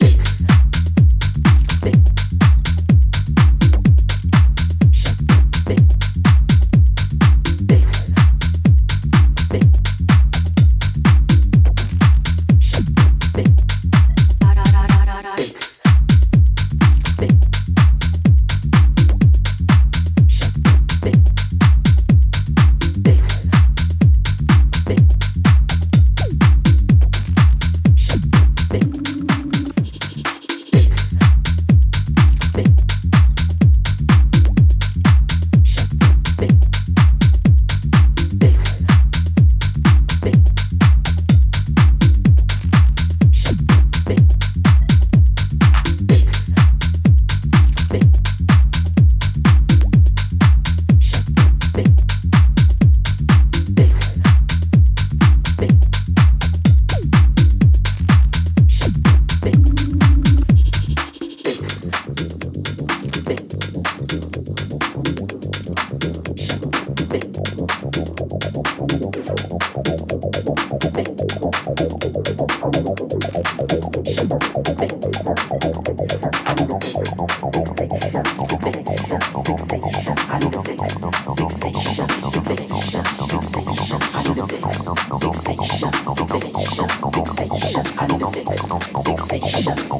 hard hitting house track
from trippy and sexy vocals to smooth cut baselines.